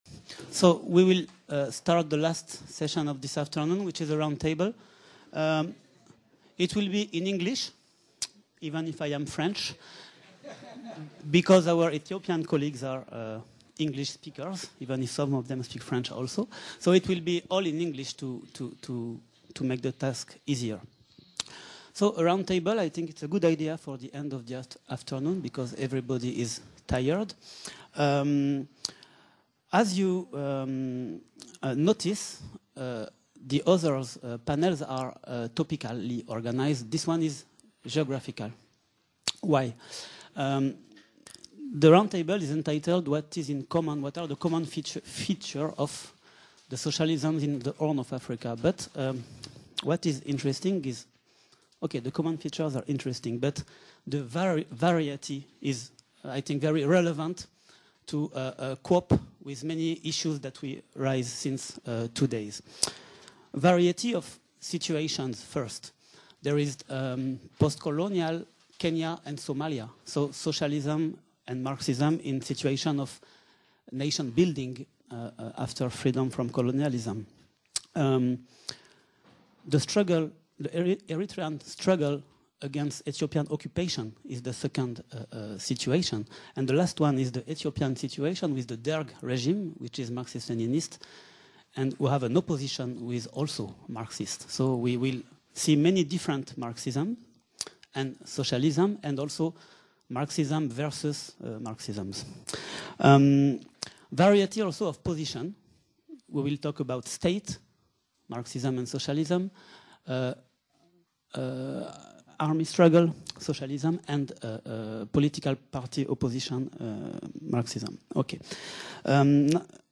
Table-Ronde .Les socialismes de la Corne de L'Afrique | Canal U